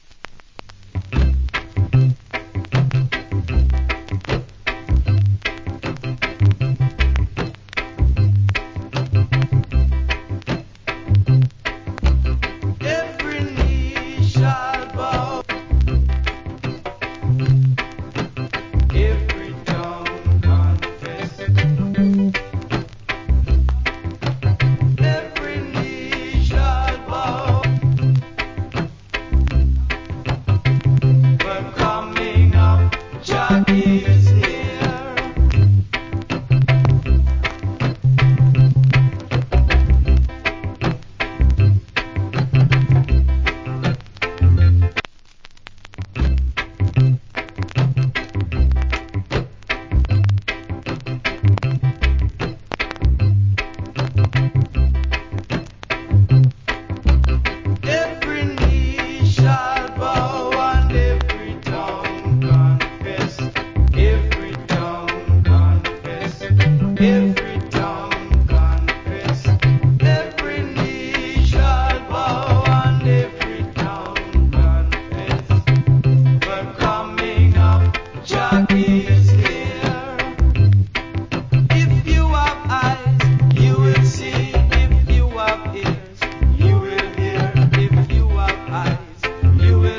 Nice Roots Rock Vocal.